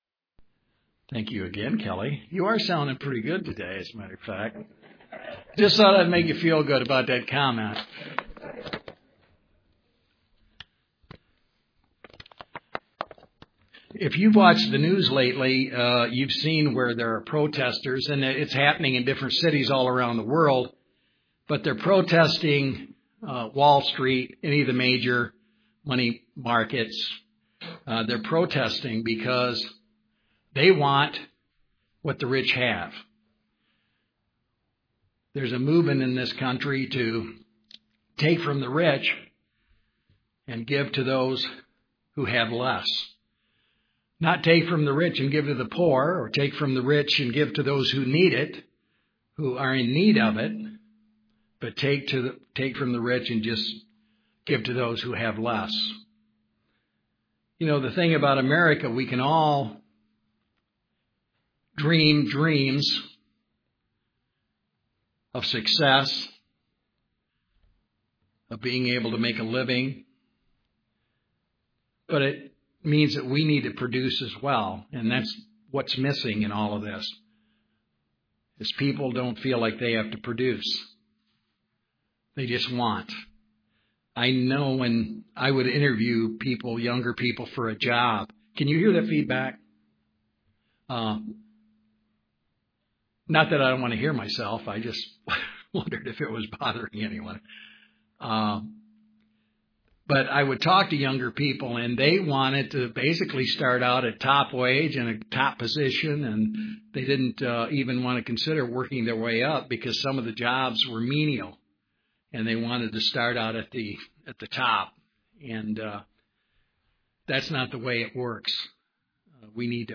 Given in Grand Rapids, MI
UCG Sermon Studying the bible?